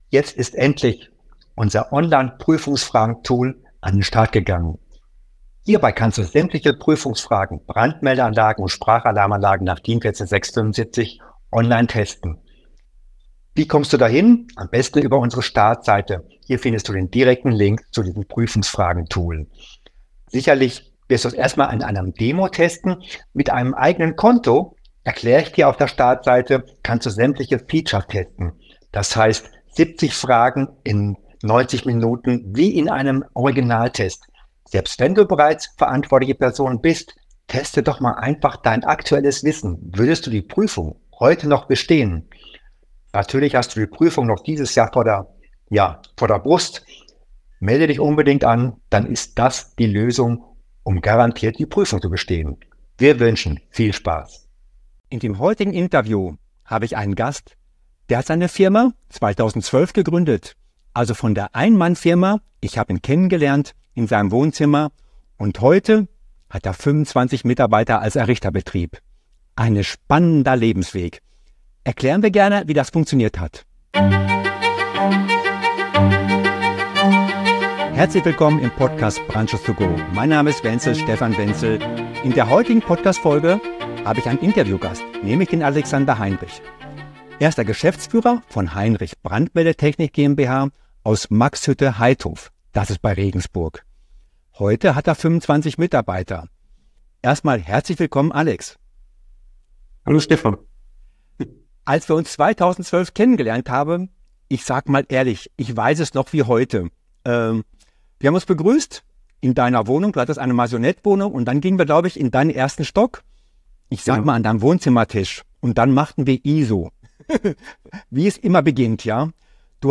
Heute habe ich einen ganz besonderen Gast für dich: